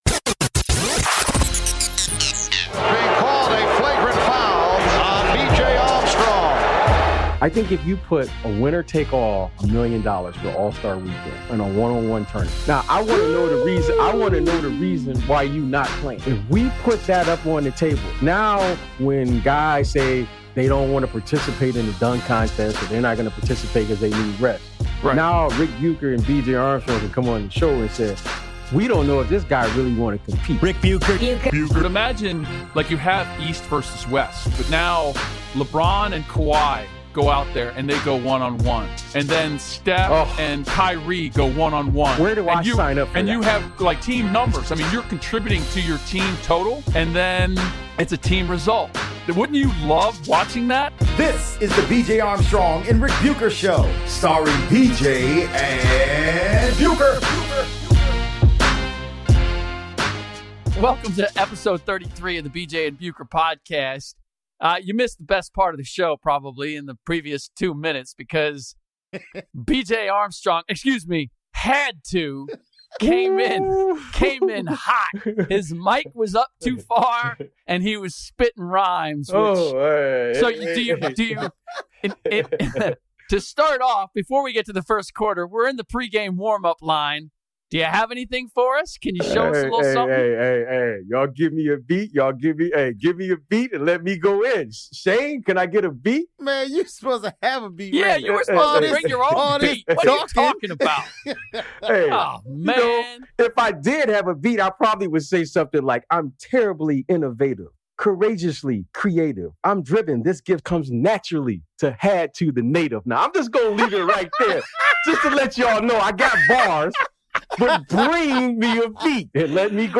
Golden State Warriors Forward MATT BARNES stops by to discuss his bad boy persona, how he has managed to stay in the NBA for more than a decade, and what he thinks of his return to the Dubs.